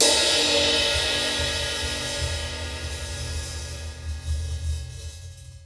Twisting 2Nite Crash.wav